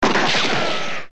gunshot.mp3